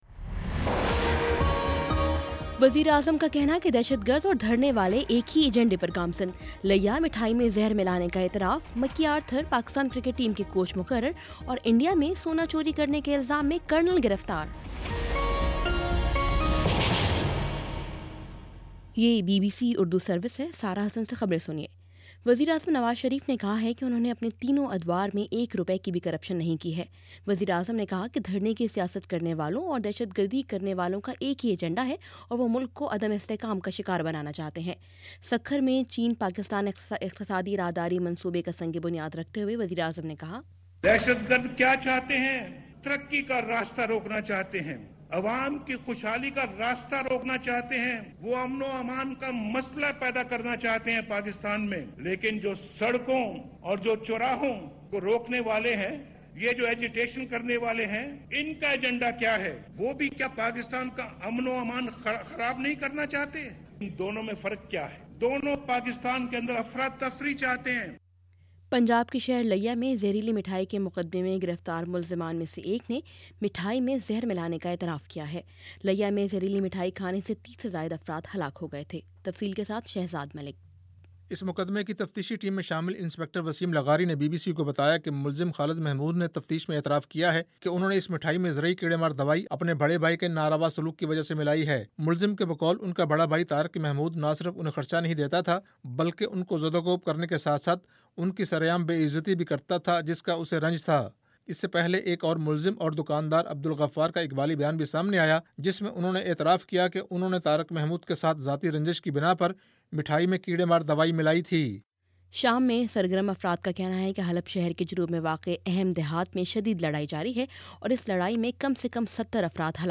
مئی 06 : شام پانچ بجے کا نیوز بُلیٹن